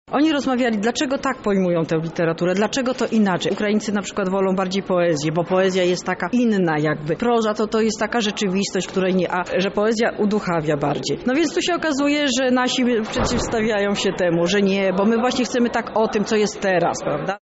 „Jurorzy nie mieli łatwego zadania, bo ścierają się tu dwie szkoły” – mówi